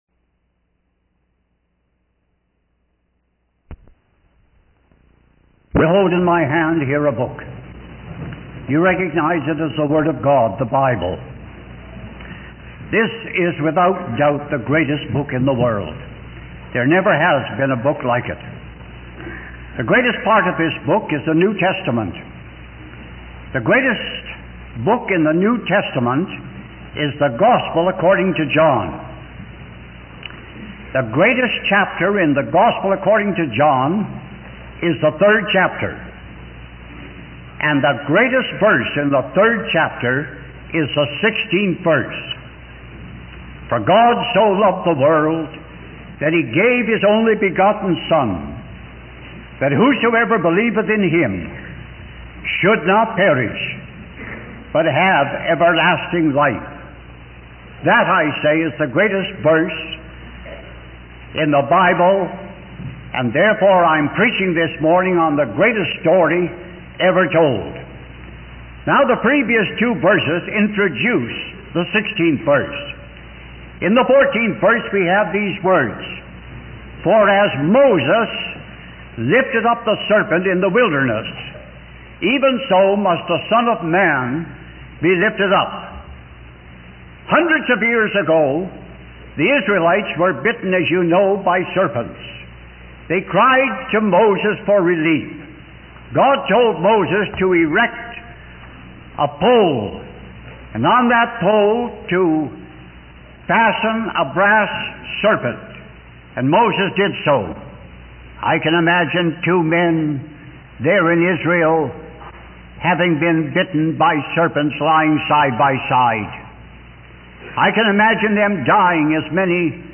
In this sermon, the preacher emphasizes that salvation starts with God, not with man. God's love for the world led Him to give His only begotten Son, Jesus, to die on the cross for the sins of humanity.